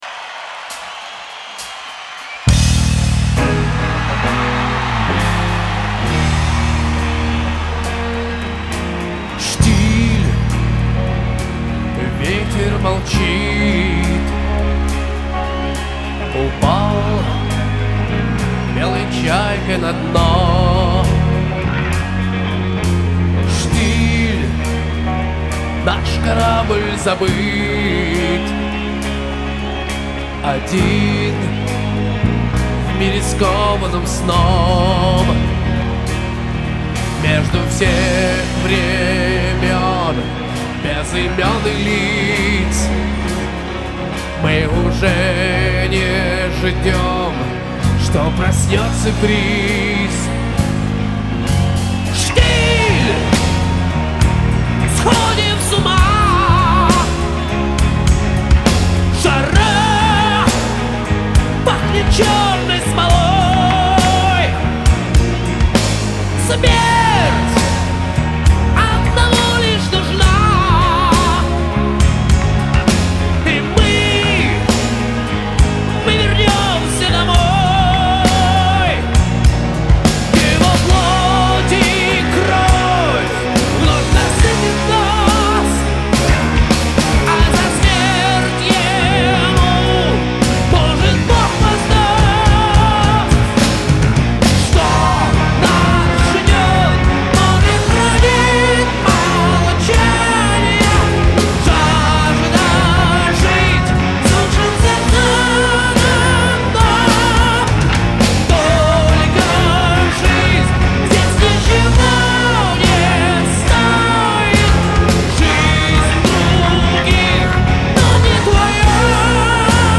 Жанр: Heavy Metal